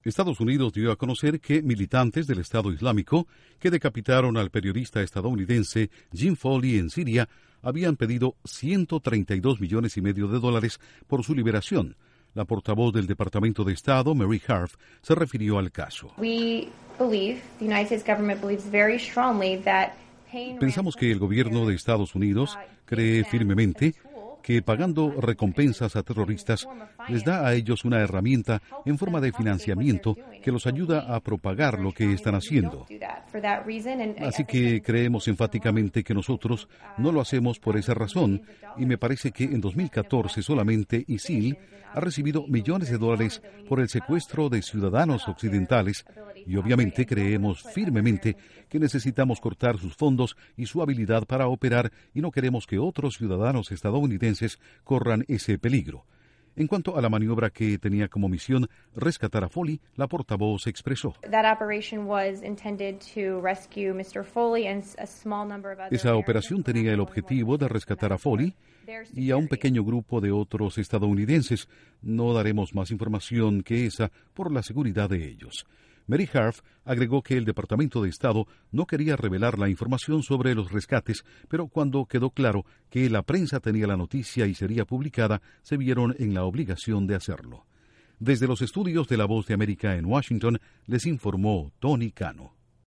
Estados Unidos deja clara su posición con respecto a las recompensas que piden los terroristas por liberación de estadounidenses. Informa desde los estudios de la Voz de América en Washington